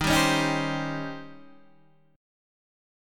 D#13 chord